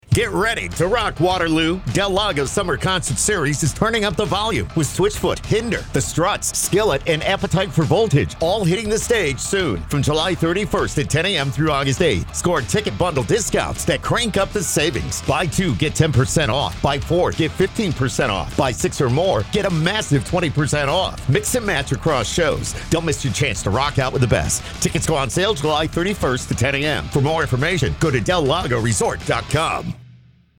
Middle Aged
Senior